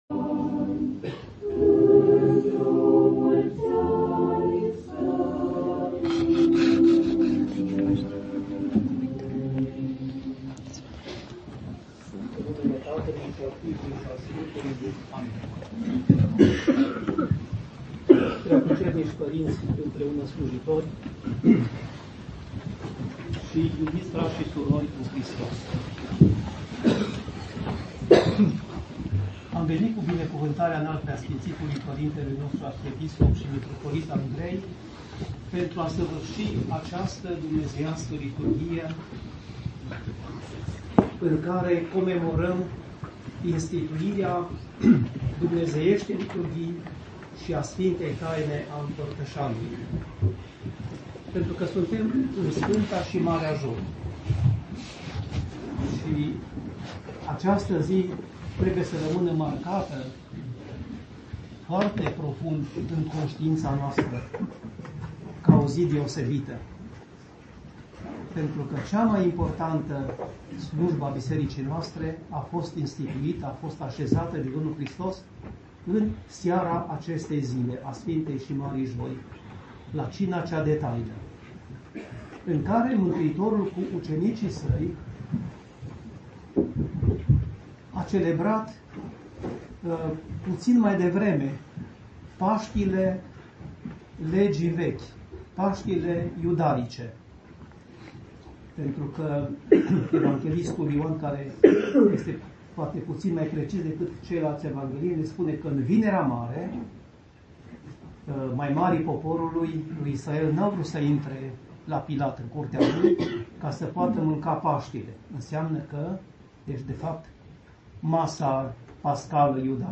PS Samuel Bistrițeanul - Cuvânt la Liturghia din Joia Mare